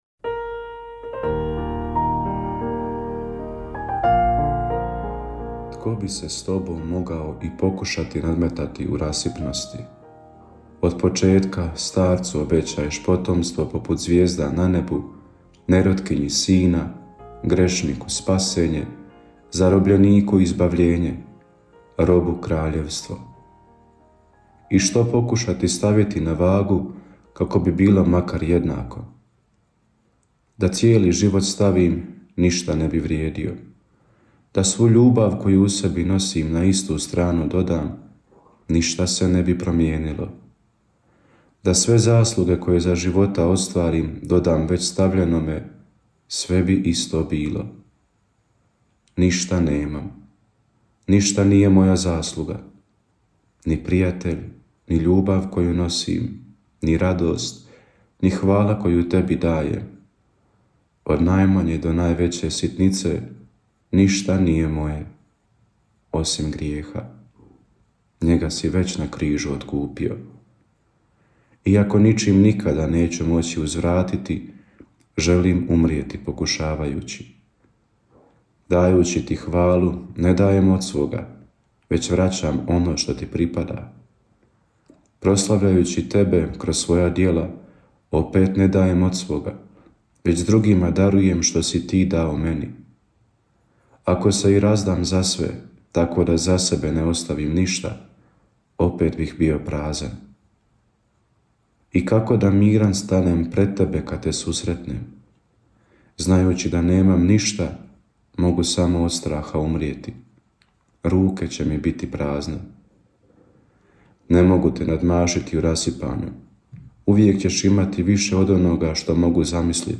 Kratku emisiju ‘Duhovni poticaj – Živo vrelo’ slušatelji Radiopostaje Mir Međugorje mogu slušati od ponedjeljka do subote u 3 sata, te u 7:10.